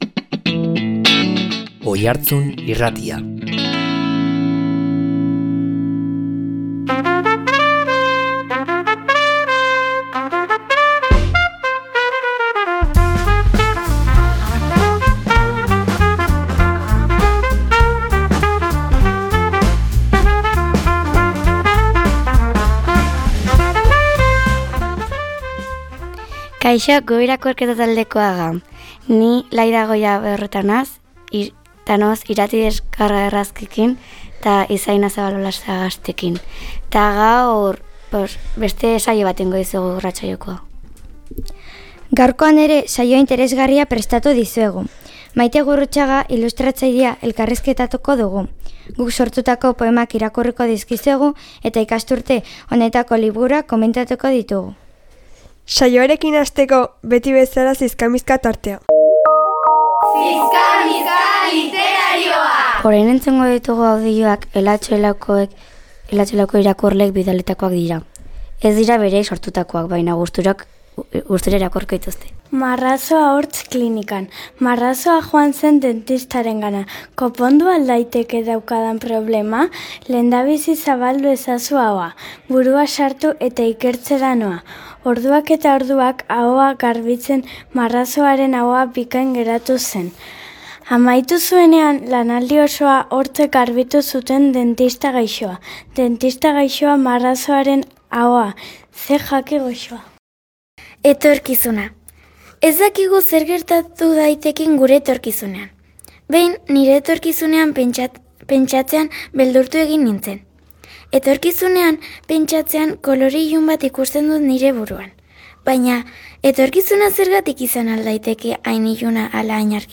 Hilabeteroko lez, irakurle taldeko lagunak izan ditugu irratian Hitz eta Pitz saioan.